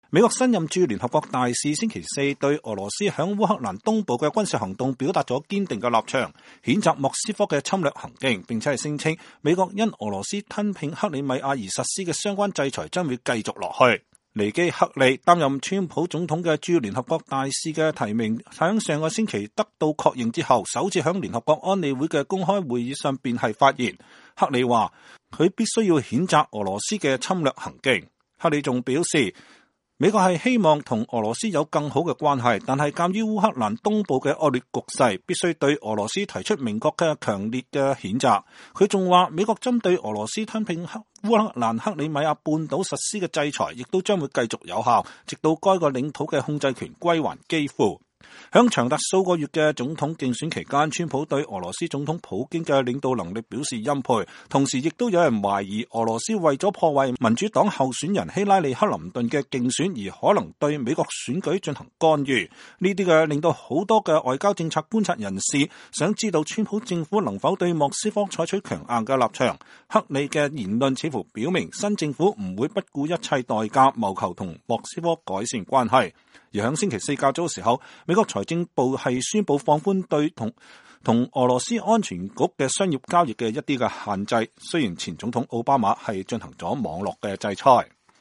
２０１７年２月２日，美國新任駐聯合國大使尼基•黑利在安理會會議上首次發言。